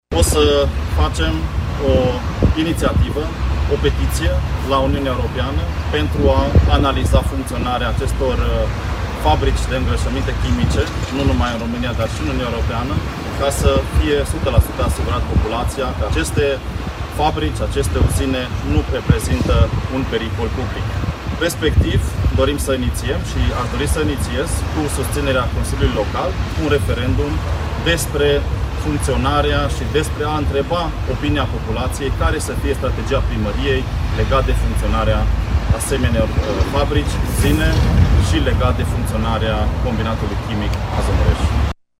Soós Zoltán a mai spus că va adresa și o petiție către reprezentanții Uniunii Europene: